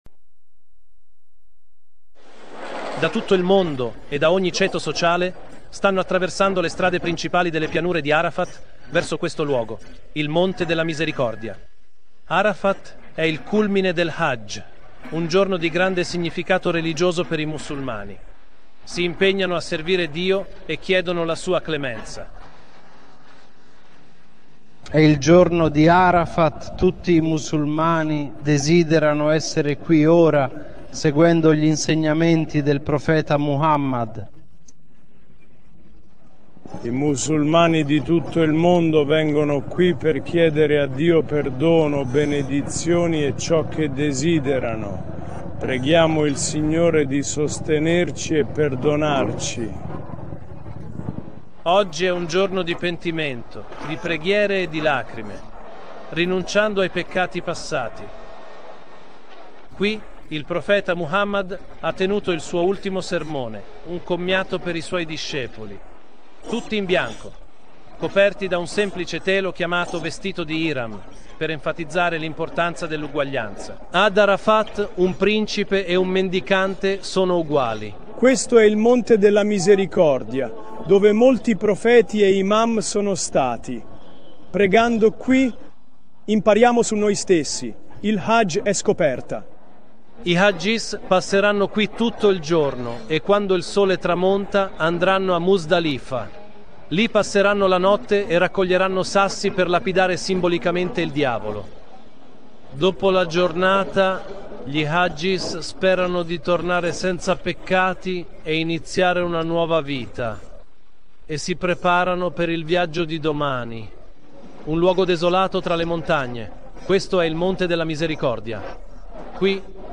Descrizione: Questo video è una copertura giornalistica di Al-Jazeera sulla virtù del Hajj.